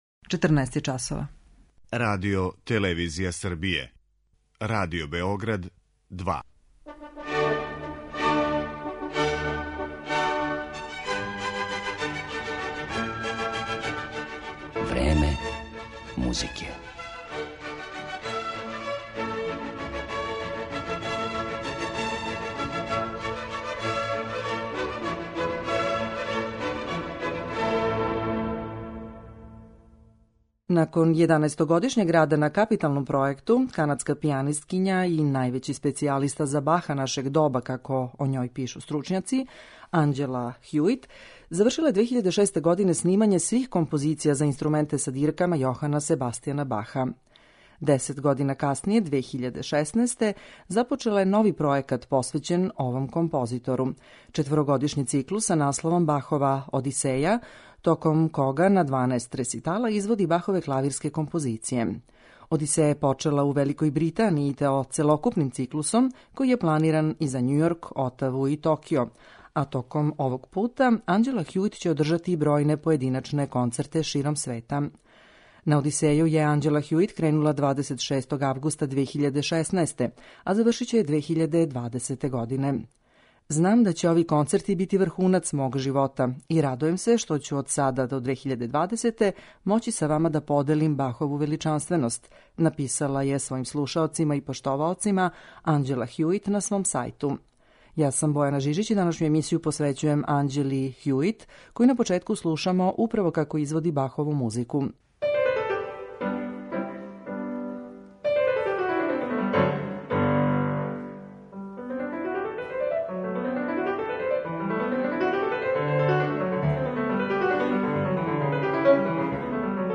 Једном од највећих специјалиста за извођење музике Јохана Себастијана Баха на клавиру, канадској пијанисткињи Анђели Хјуит